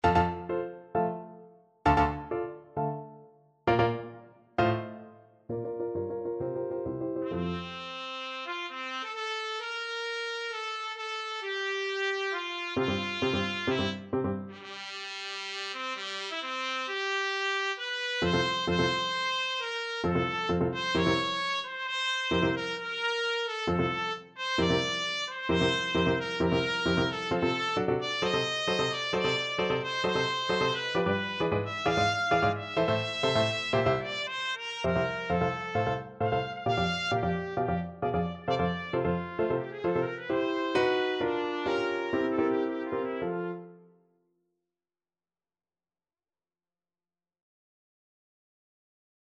Classical Berlioz, Hector Idee fixe from Symphonie Fantastique Trumpet version
F major (Sounding Pitch) G major (Trumpet in Bb) (View more F major Music for Trumpet )
2/2 (View more 2/2 Music)
Allegro agitato e appassionato assai = 132 (View more music marked Allegro)
Classical (View more Classical Trumpet Music)